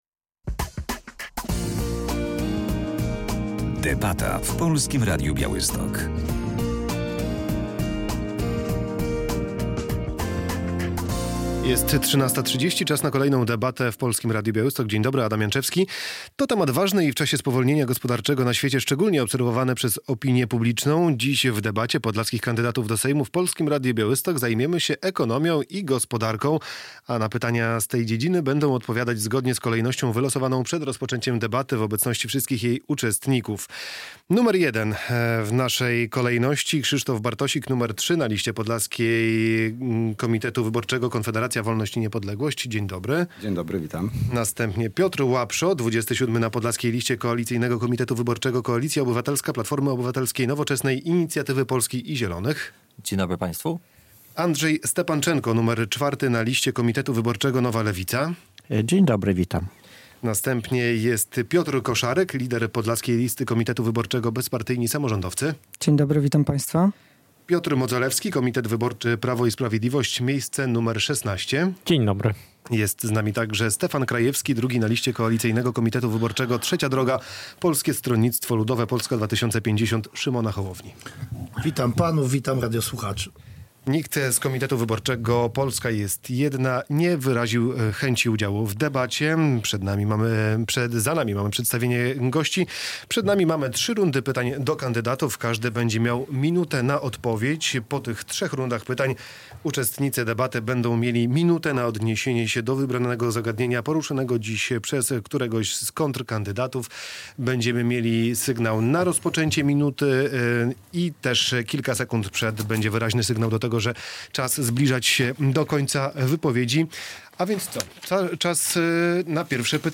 To temat ważny i w czasie spowolnienia gospodarczego na świecie, szczególnie obserwowany przez opinię publiczną. W czwartek (5.10) w debacie podlaskich kandydatów do Sejmu w Polskim Radiu Białystok zajmowaliśmy się ekonomią.